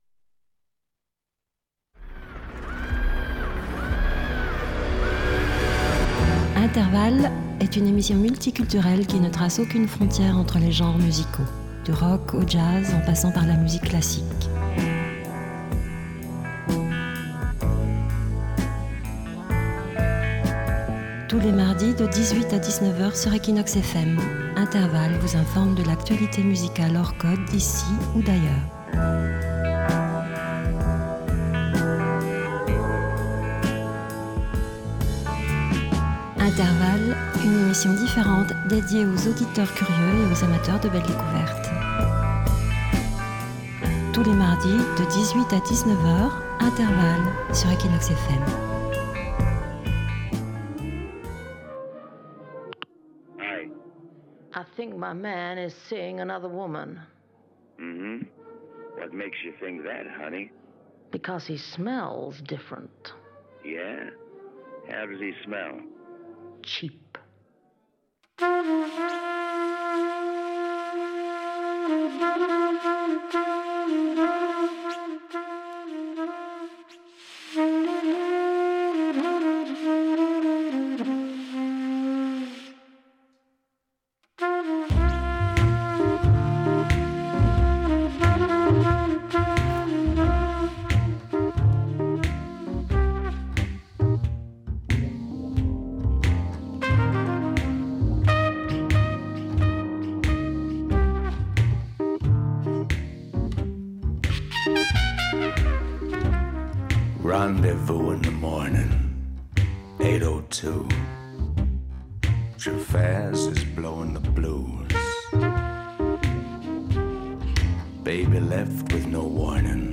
Pour ce quatrième épisode (et pour le suivant aussi), on propose aux amateurs de rock d’écouter un peu de jazz.